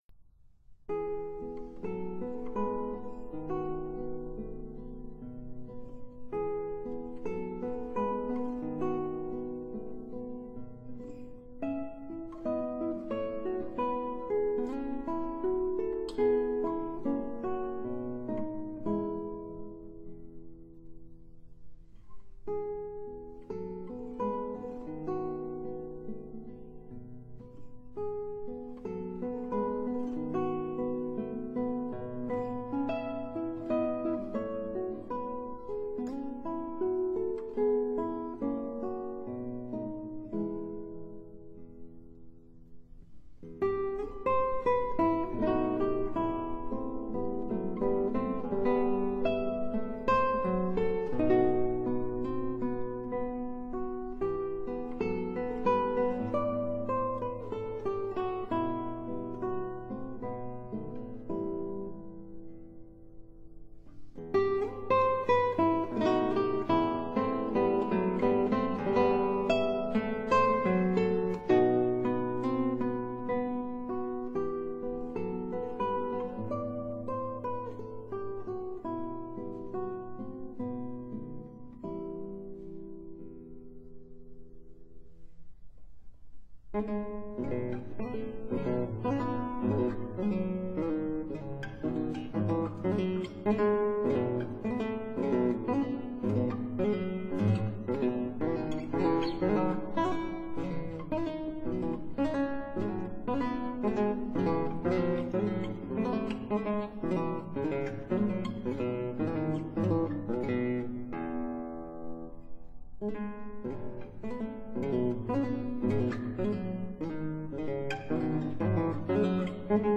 古典音樂
guitar Date